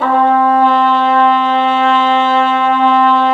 Index of /90_sSampleCDs/Roland L-CDX-03 Disk 2/BRS_Cup Mute Tpt/BRS_Cup Ambient